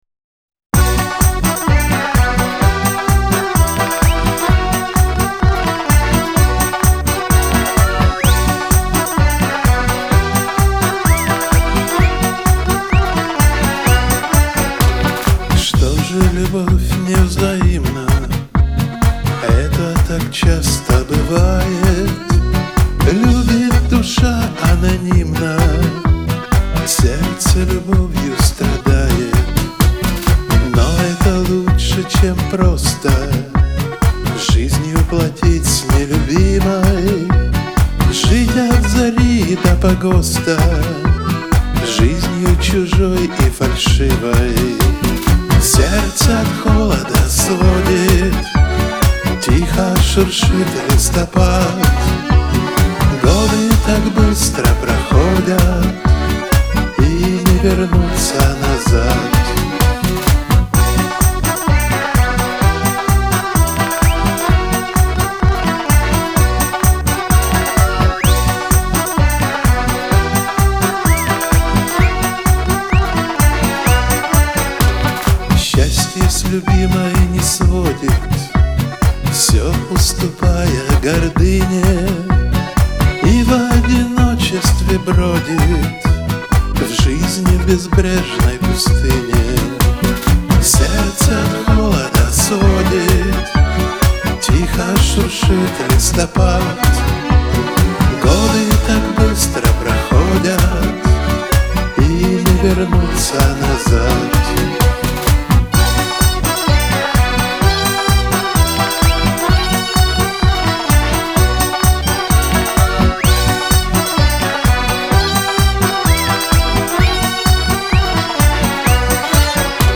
Шансон , грусть , Лирика